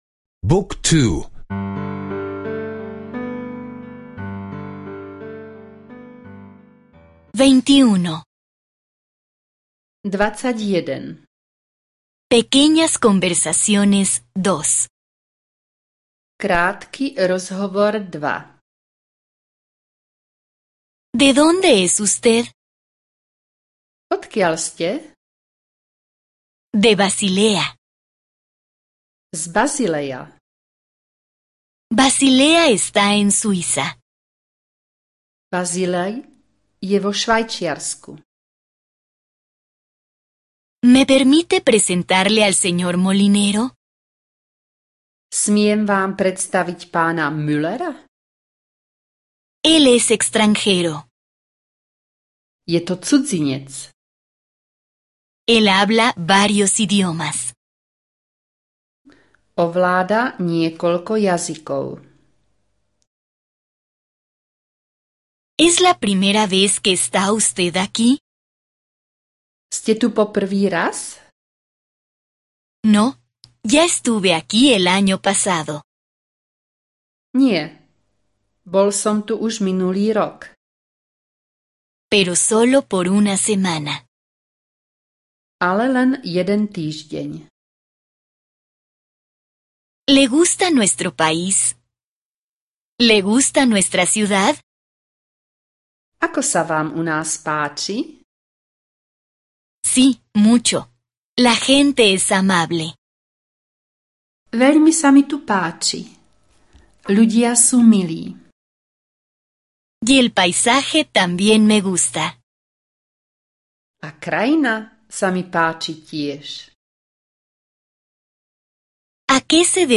Curso de audio de eslovaco (escuchar en línea)